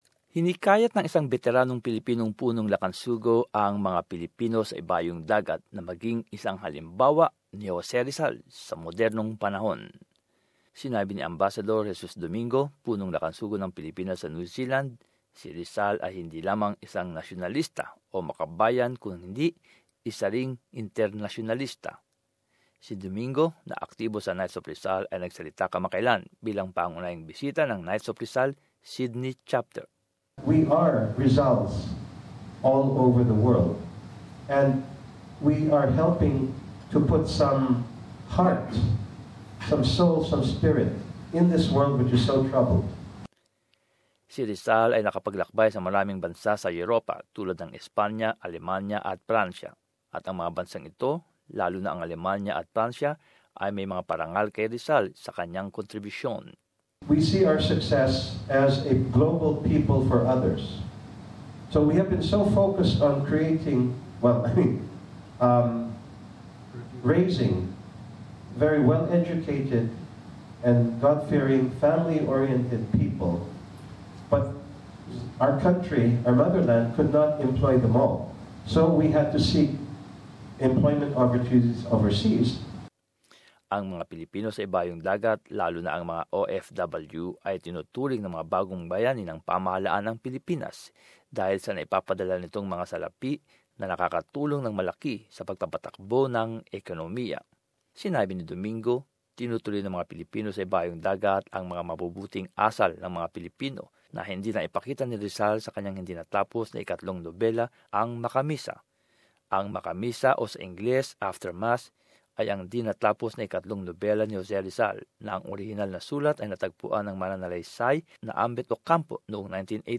Ito ang pinaka-tema sa talumpati ni Domingo sa mga miyembro ng Knights of Rizal, Sydney Chapter, sa nakaraang seremonya ng pagbigay gawad